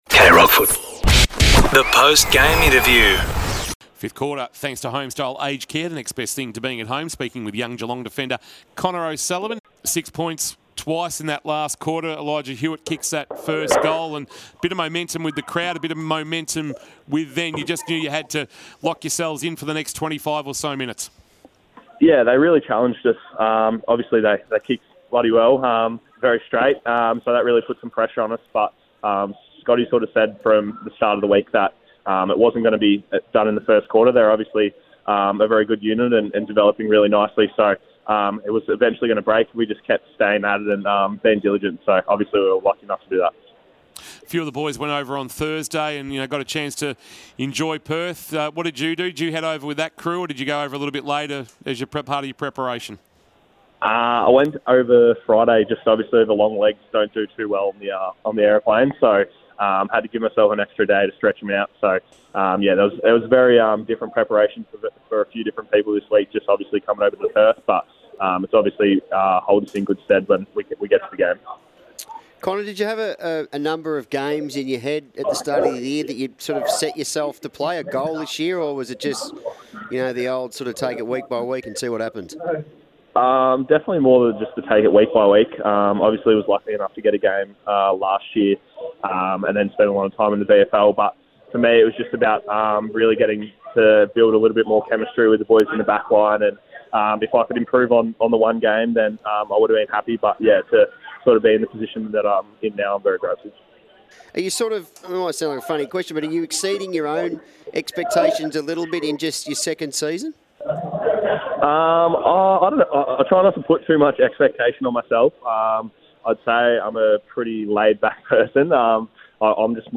2025 - AFL - Round 12 - West Coast vs Geelong: Post-match interview